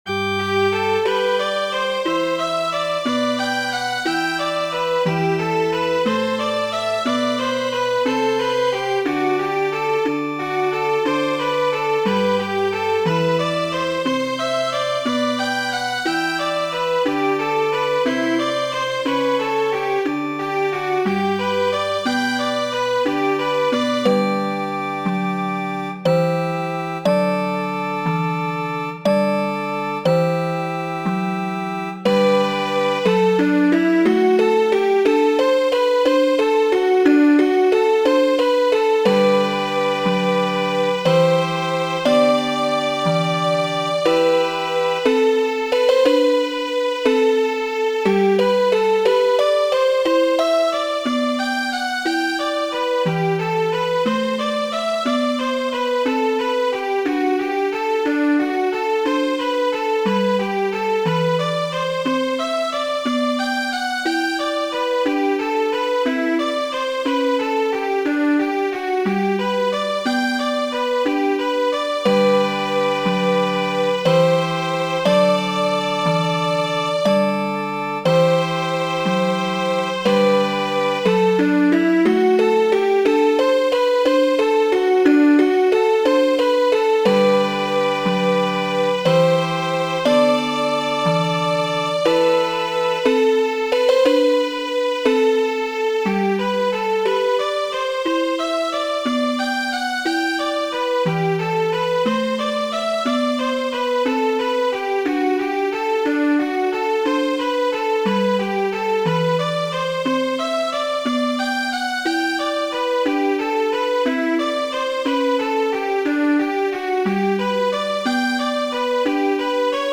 Jesu', deziro de la ĝojo de homo, korusa kanto de J. S. Bach, en aparta versio de mi mem.